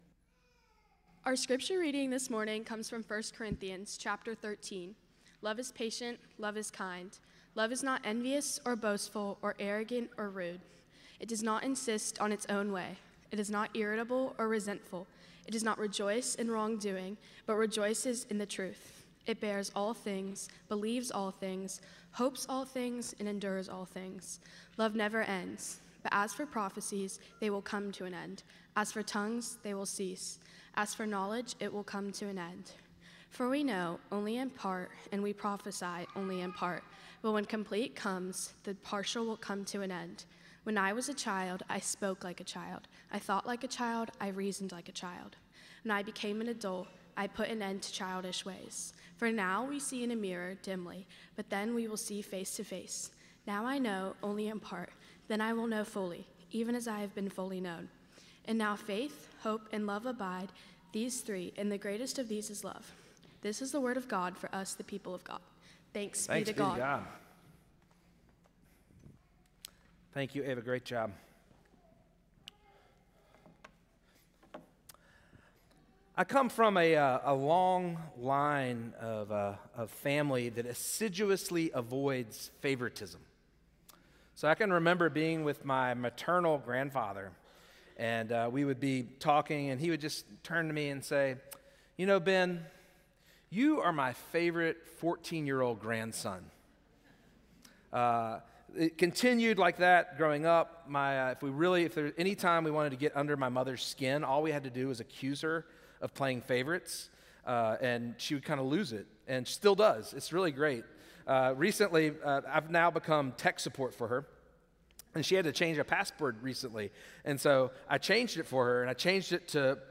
First Cary UMC's First on Chatham Sermon "Love Never Ends&rdquo